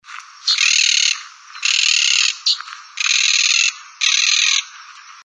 Monk Parakeet (Myiopsitta monachus)
Sex: Indistinguishable
Location or protected area: Reserva Ecológica Costanera Sur (RECS)
Condition: Wild
Certainty: Recorded vocal
RECS.Cotorras-comunes-VN.mp3